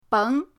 beng2.mp3